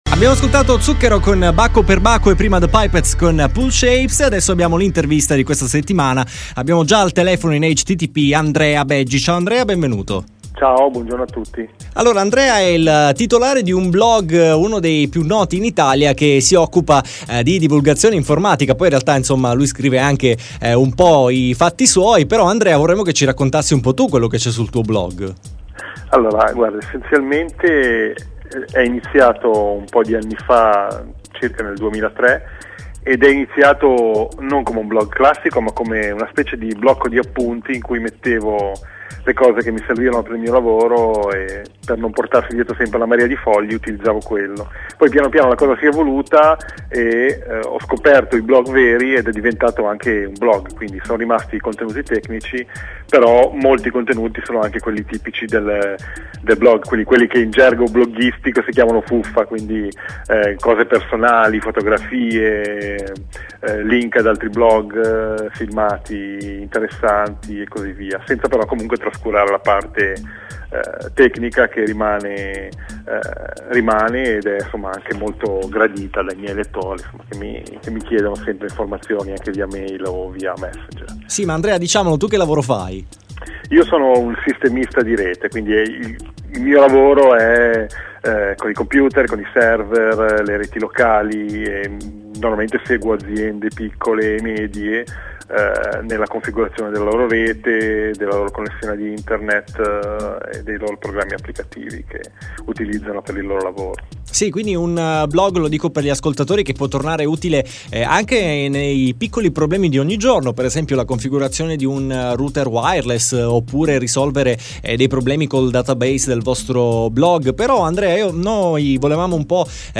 Intervista citata